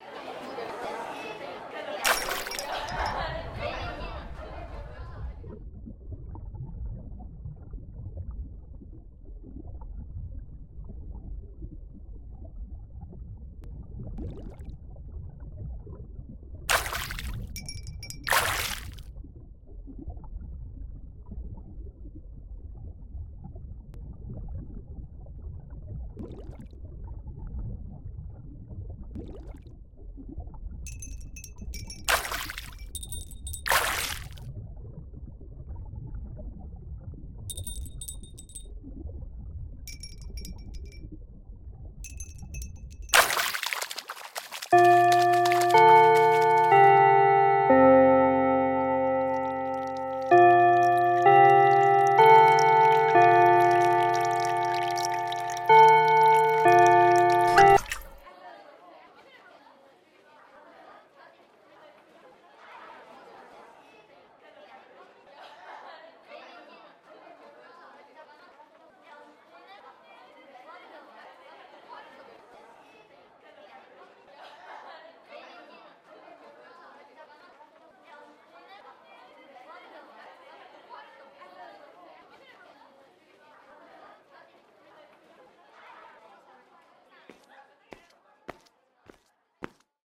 【声劇】稚魚と貴方